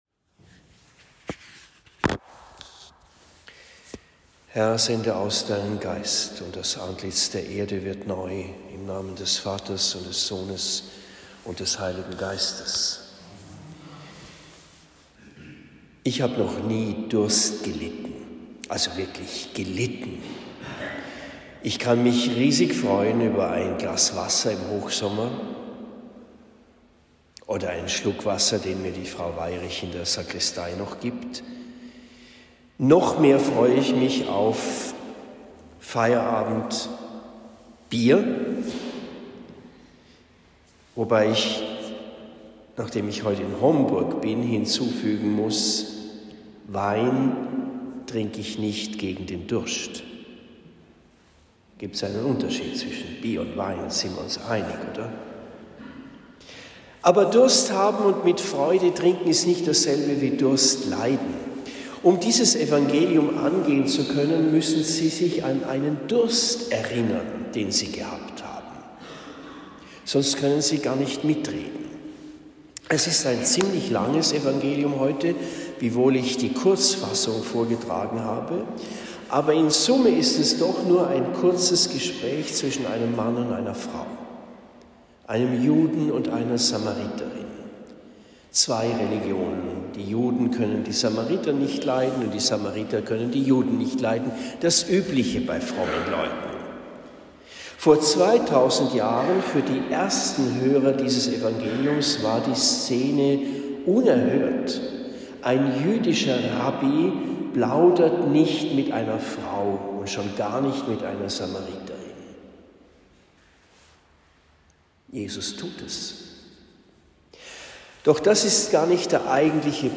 Dritter Fastensonntag - Predigt in Homburg